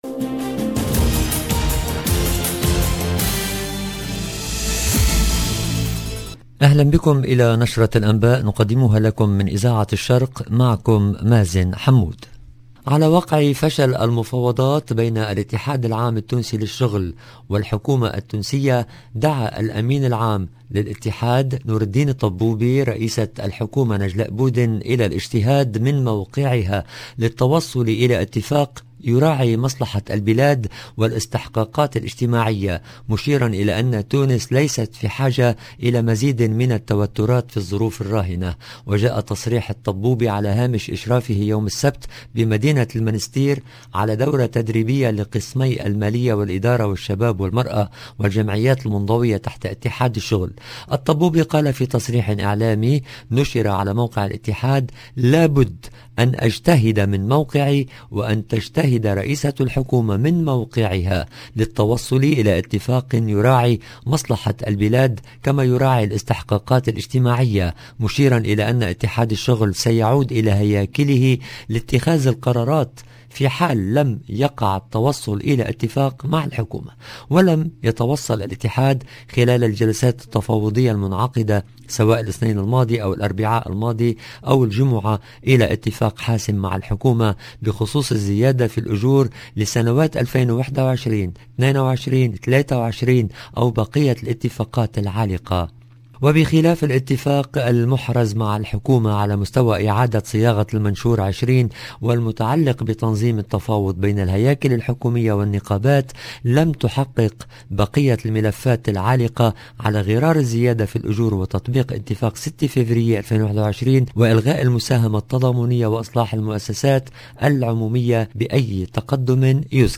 LE JOURNAL EN LANGUE ARABE DU SOIR DU 4/09/22